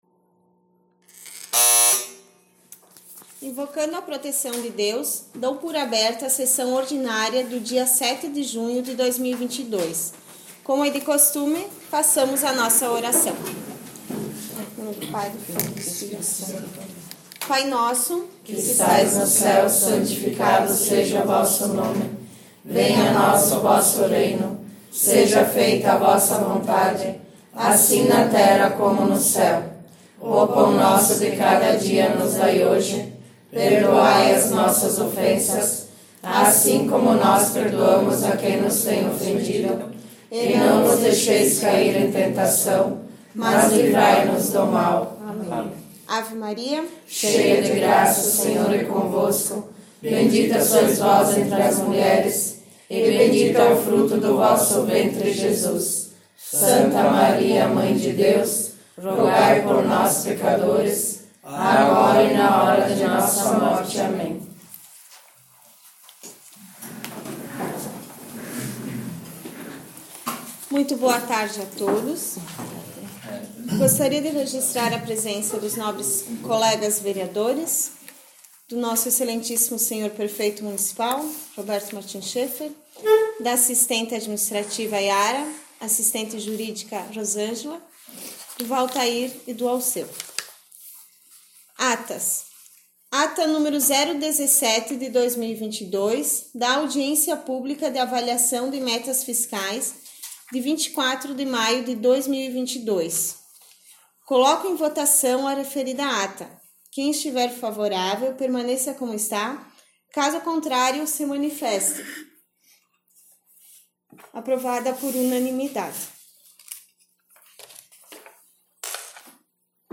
15 - Sessão Ordinária, na 07 de jun 17.59.mp3 — Câmara Municipal de Boa Vista do Sul
Áudio/Gravação das Sessões da Casa Legislativa Todos os Áudios ÁUDIO SESSÕES 2021 ÁUDIO DAS SESSÕES 2020 ÁUDIO DAS SESSÕES 2019 ÁUDIO DAS SESSÕES 2022 7 - Sessão Ordinária 22 de março 15 - Sessão Ordinária, na 07 de jun 17.59.mp3